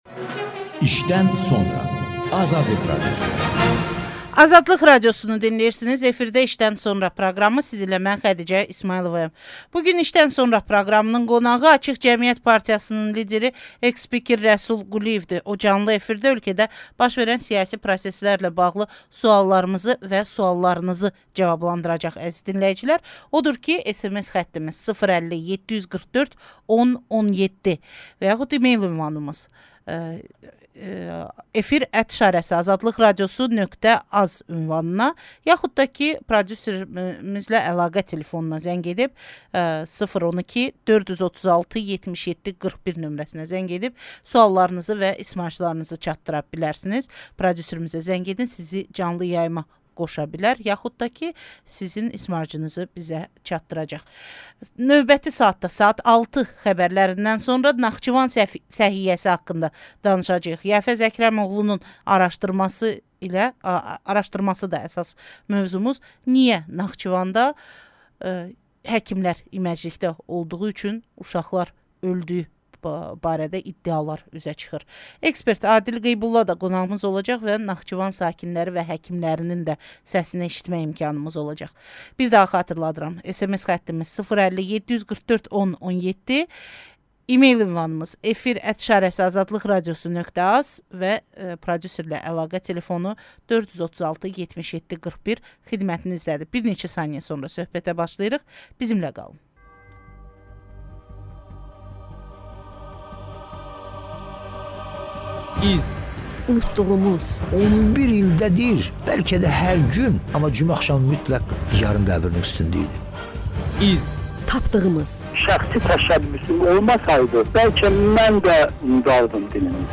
İşdən sonra - Rəsul Quliyev canlı efirdə...